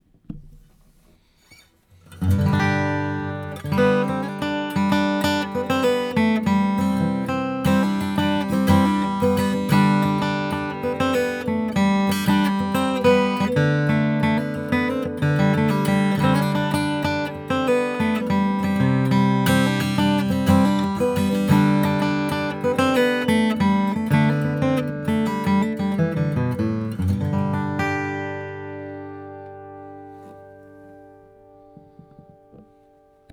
bridge pin sound sample
Suda with Antique Acoustic pins Suda with ebony pins